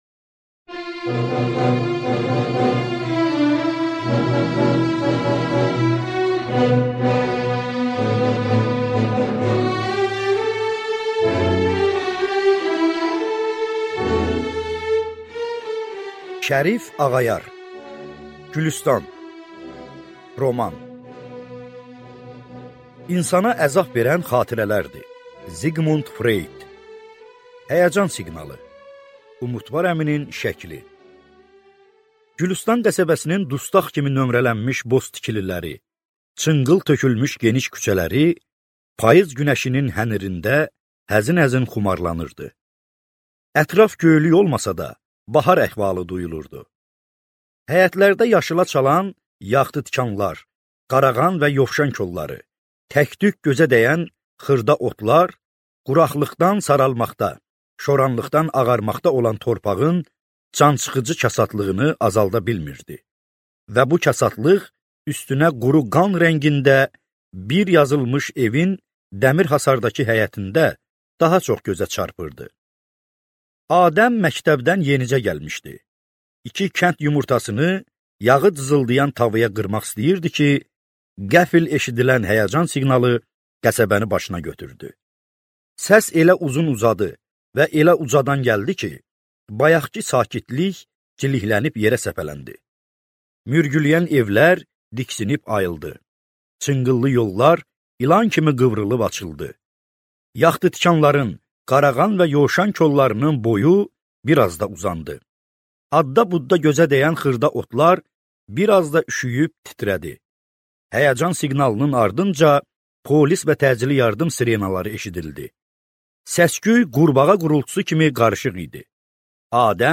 Аудиокнига Gülüstan | Библиотека аудиокниг